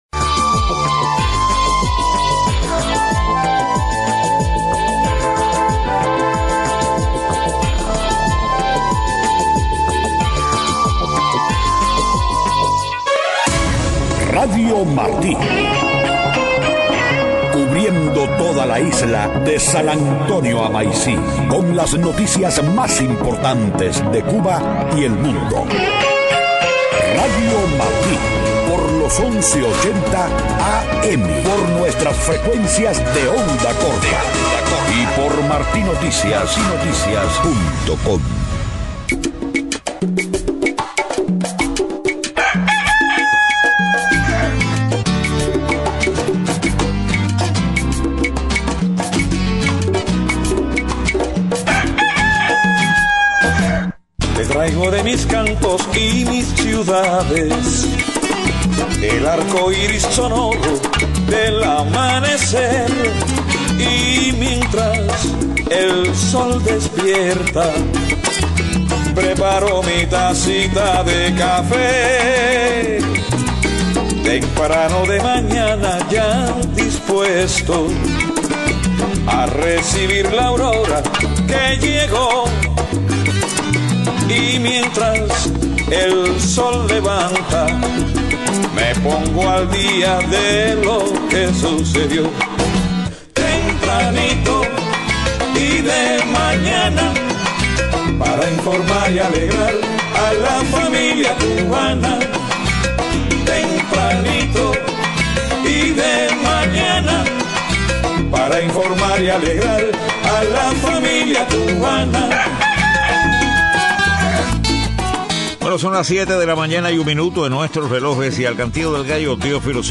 The URL has been copied to your clipboard No media source currently available 0:00 0:49:26 0:00 Descargar | Escúchelo en vivo como MP3 7:00 a.m Noticias: Paquito D’ Rivera critica a artistas cubanos o extranjeros que se presentan en Cuba.